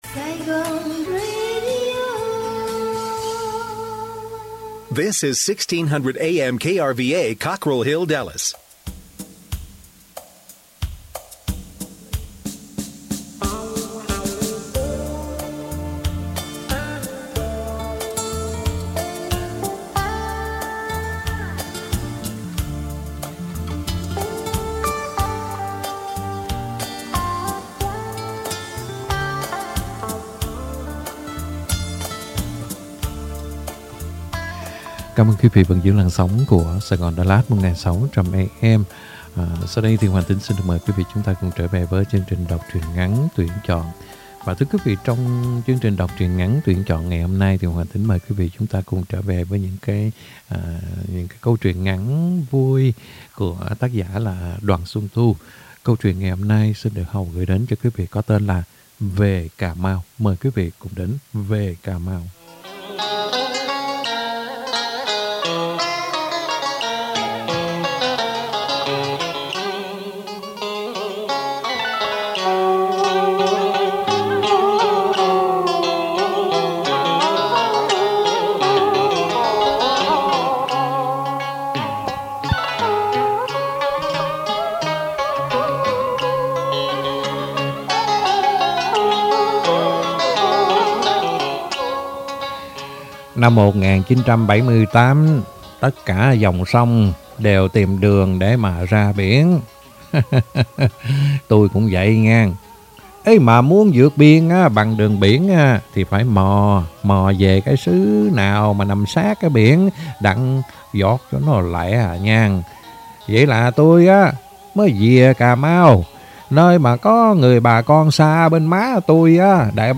Đọc Truyện Ngắn = Về Cà Mau !